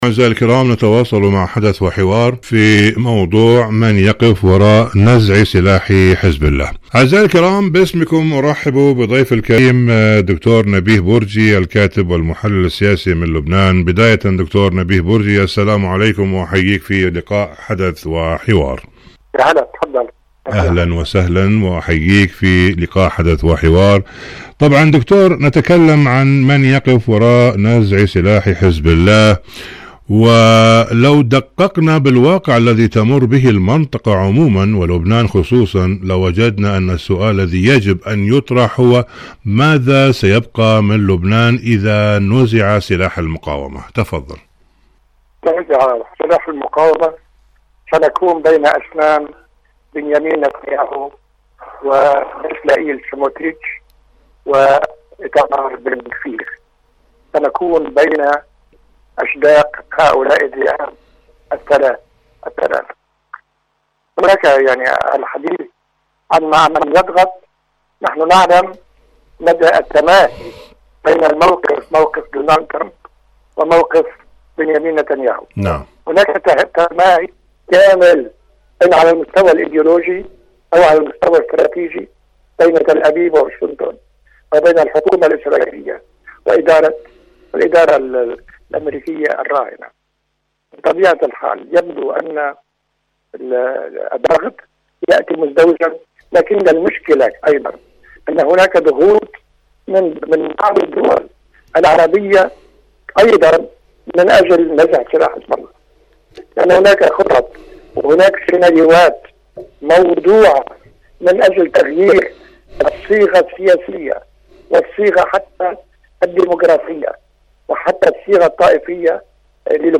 مقابلات حزب الله برامج إذاعة طهران العربية الدفاع عن لبنان الجيش اللبناني يسلاح المقاومة كيان الاحتلال الصهيوني برنامج حدث وحوار سلاح حزب الله مقابلات إذاعية من يقف وراء نزع سلاح حزب الله؟